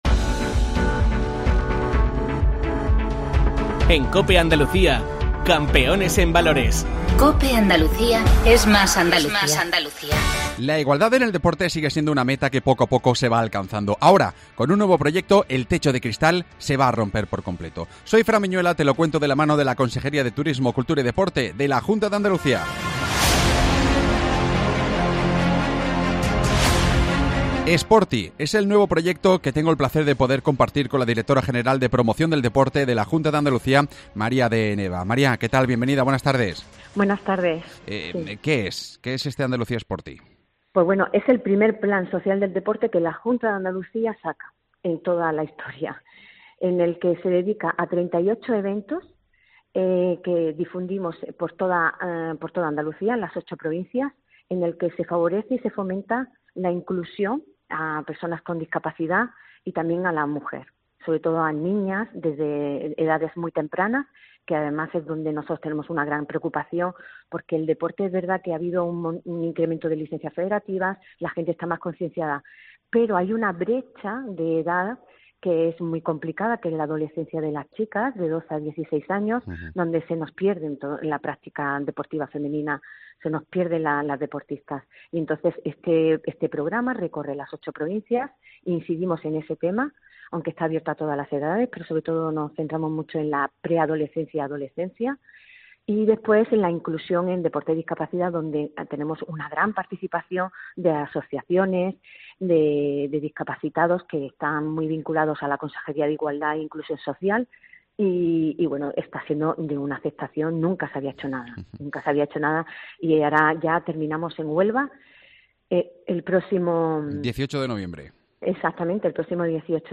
Entrevista a María de Nova, directora general de promoción deportiva de la Junta de Andalucía